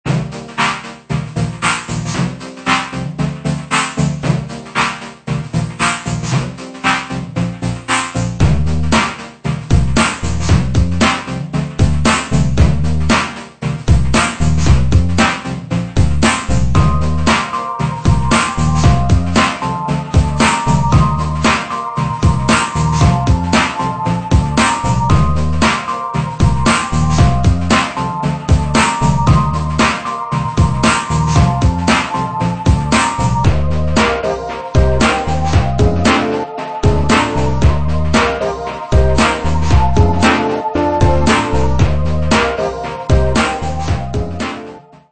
jingle harmonic medium instr.